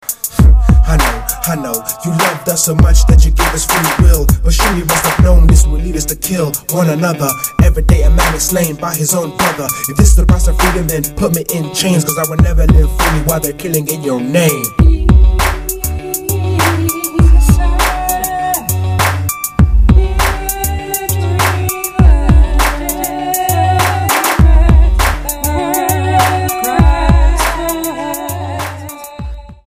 STYLE: World
If you're up for some African hip-hop this is for you.